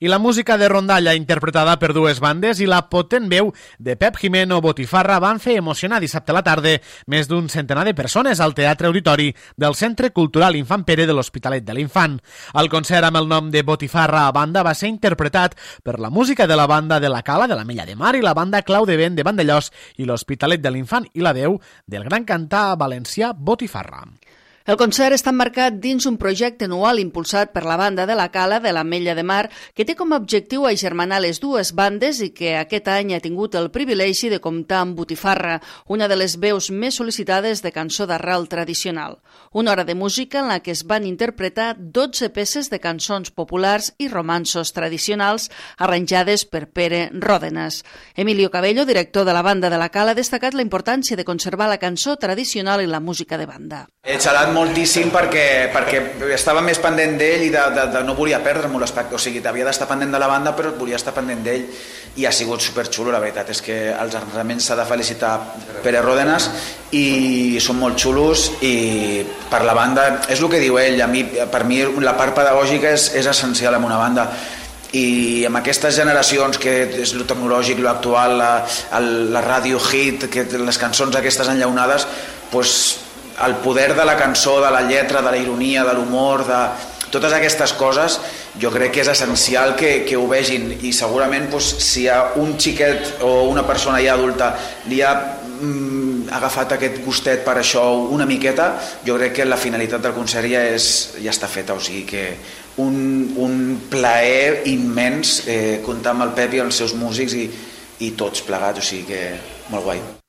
La Banda de la Cala i la Banda Clau de Vent van acompanyar el gran cantant valencià Pep Gimeno ‘Botifarra' en un concert que va reunir més d'un centenar de persones.